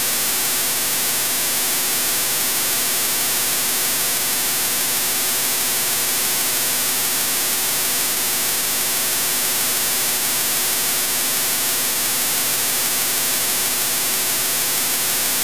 From Signal Identification Wiki Jump to: navigation , search File File history File usage CDRrecording1.mp3  (file size: 608 KB, MIME type: audio/mpeg) CDR recording mp3.